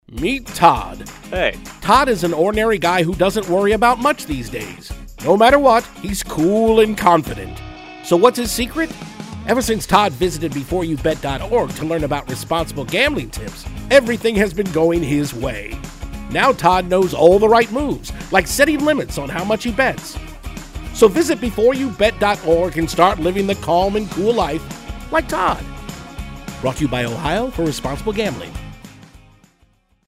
“MEET TODD” RADIO SPOT
Radio_Todd_30-2.mp3